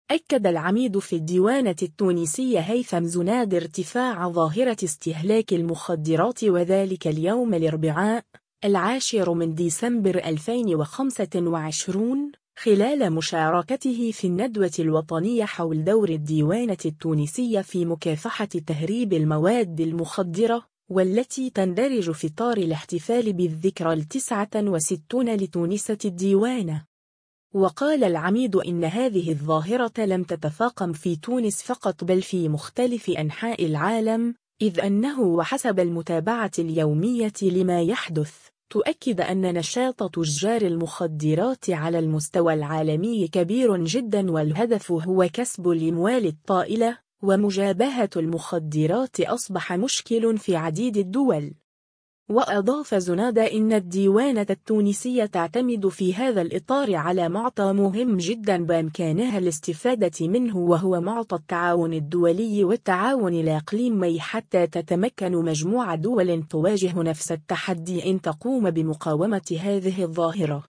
خلال مشاركته في النّدوة الوطنية حول دور الديوانة التونسية في مكافحة تهريب المواد المخدّرة